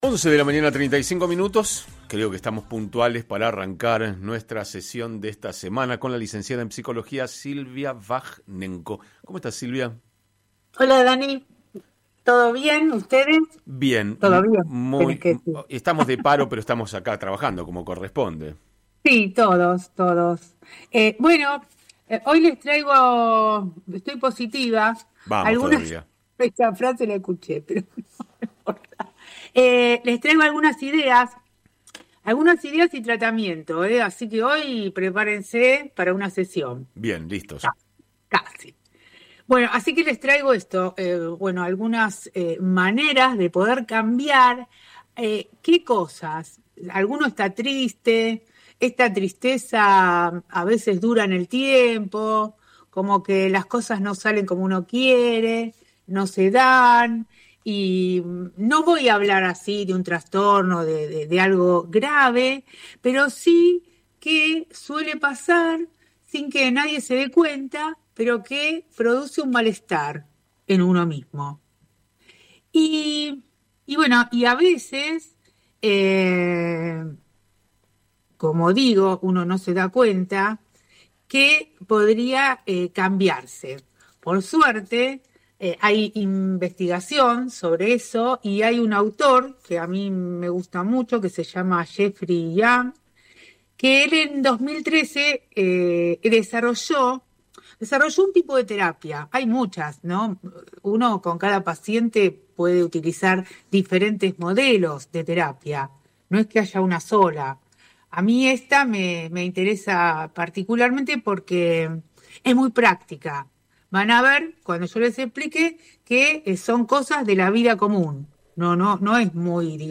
Columnistas